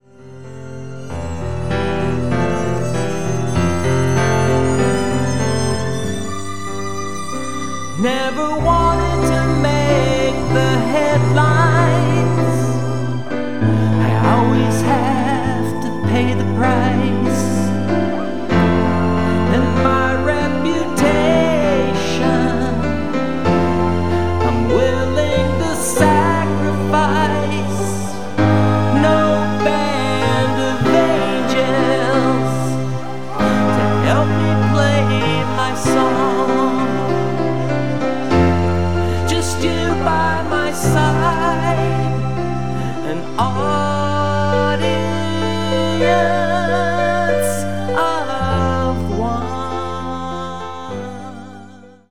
Recorded at:  Various home studios in the USA,